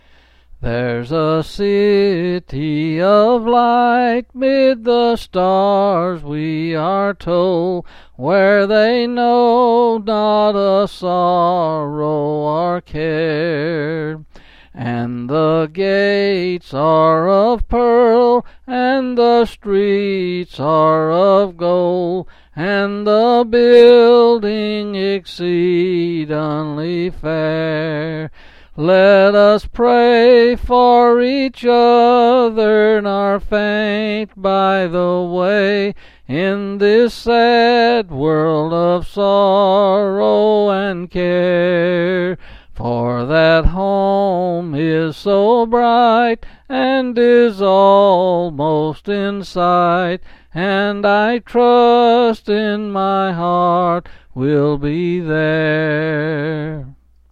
Quill Pin Selected Hymn
12s and 9s